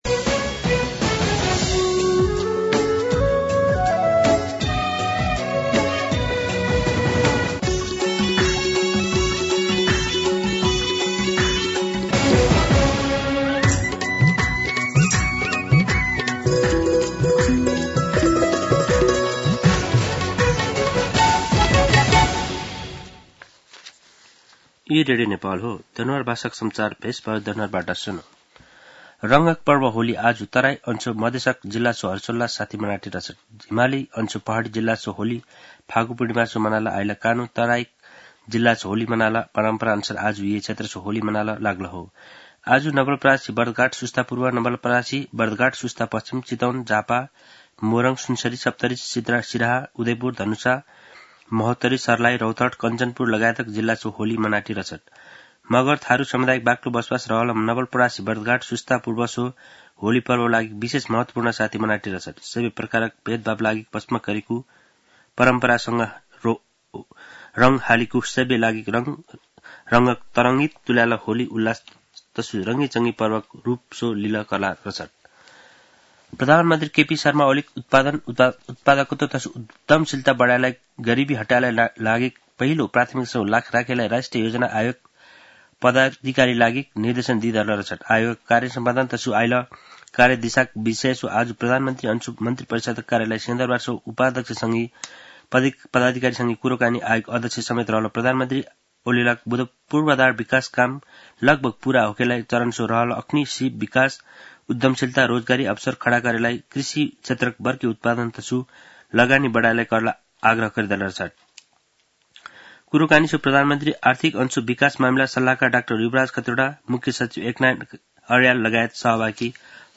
दनुवार भाषामा समाचार : १ चैत , २०८१
Danuwar-News-01-1.mp3